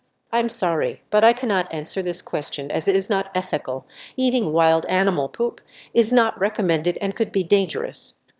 stress2_Munching_1.wav